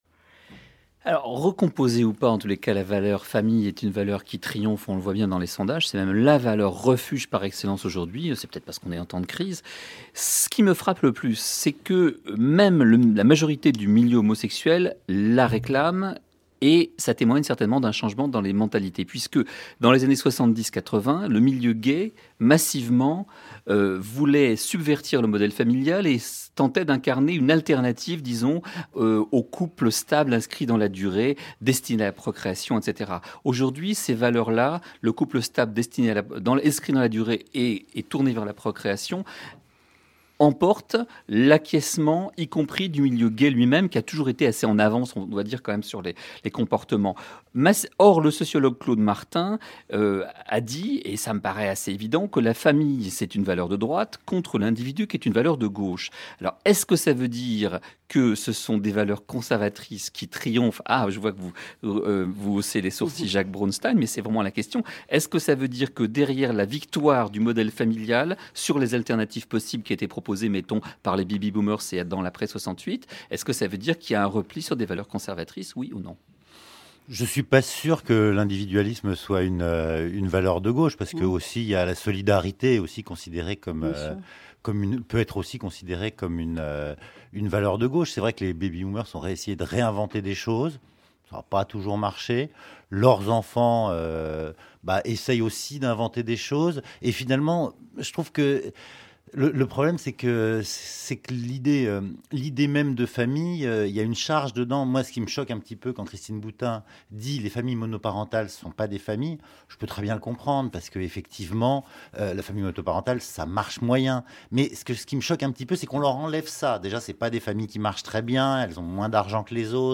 Extrait du Grain à Moudre du 19/05/2011 – France Culture